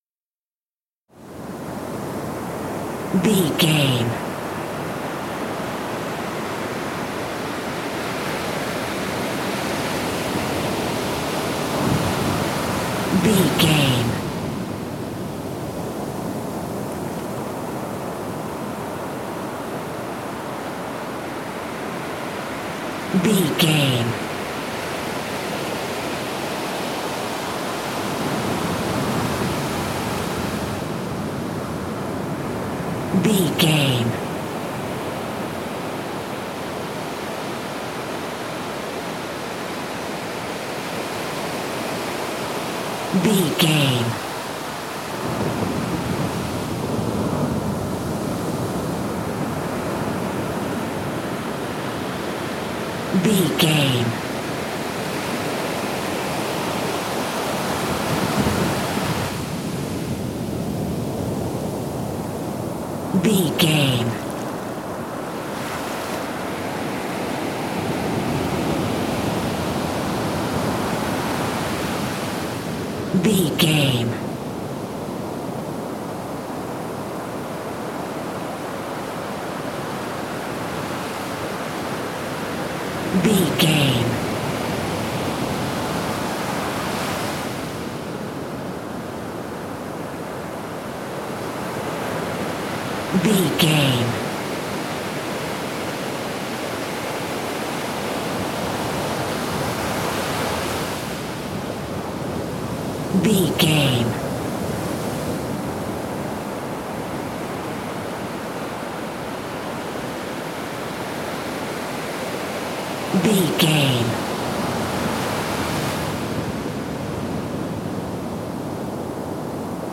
Beach ocean waves
Beach ocean waves 2
Sound Effects
calm
nature
peaceful
repetitive
ambience